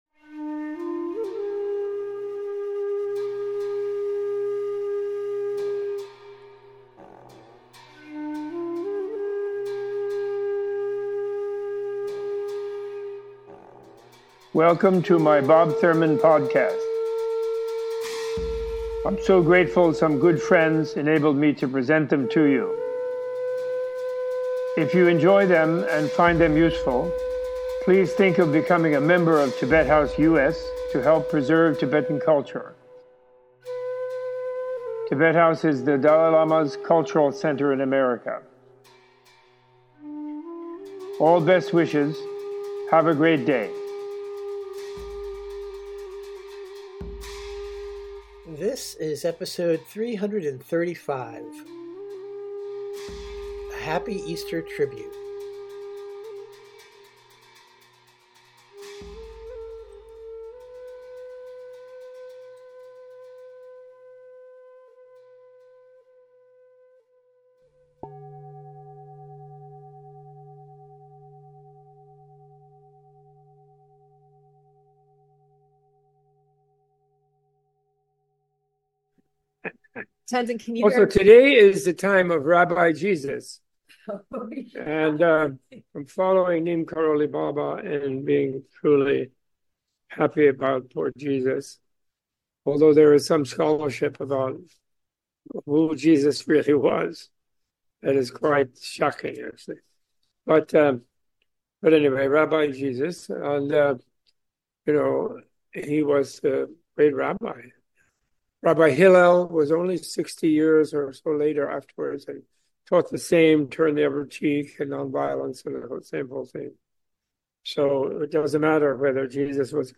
Impromptu talk given at Menla on Easter Saturday 2024 to a gathering of Yogi/nis.